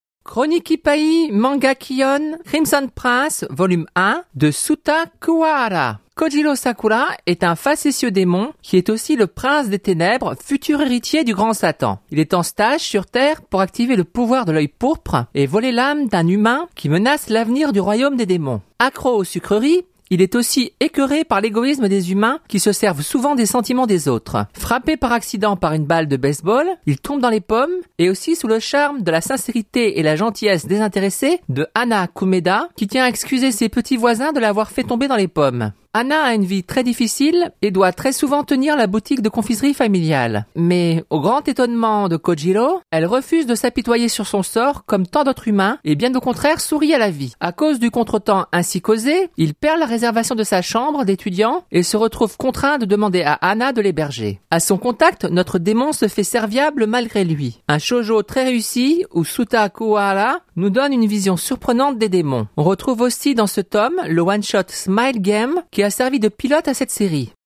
(Podcast de la chronique radio de Crimson Prince - Volume 1 )